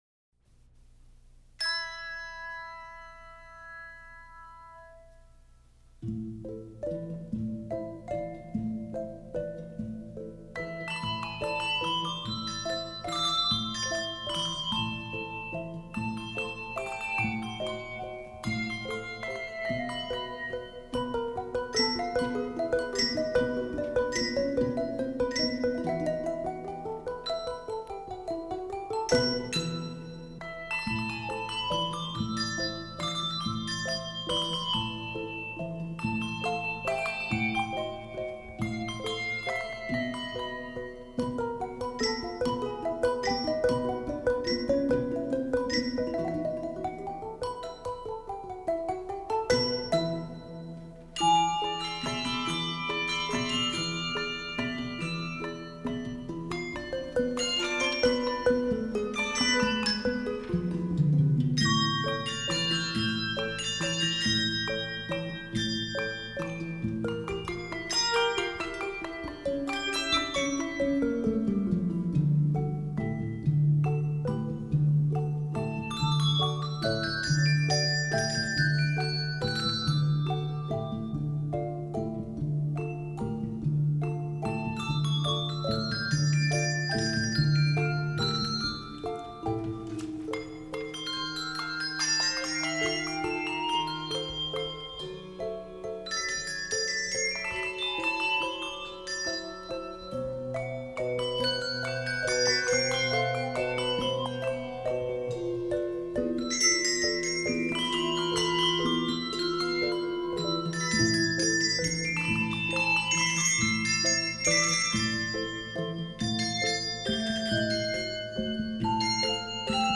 Voicing: Handbells 5-6 Octave